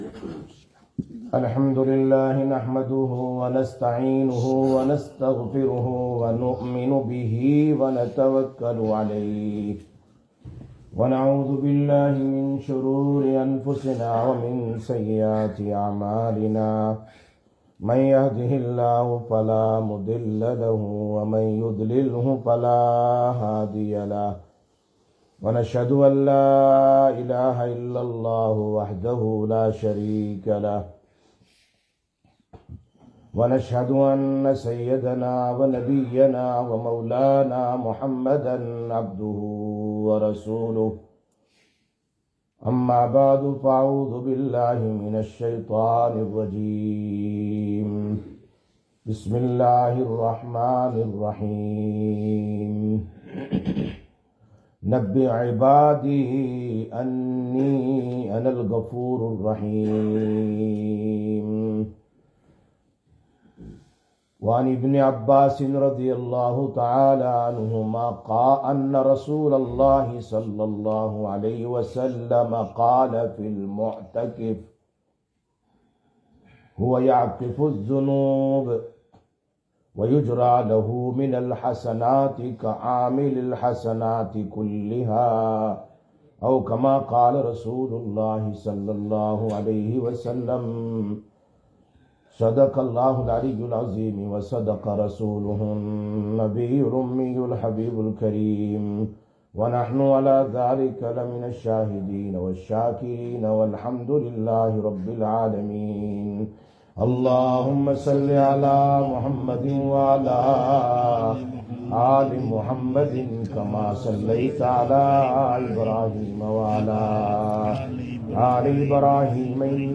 22/03/2024 Jumma Bayan, Masjid Quba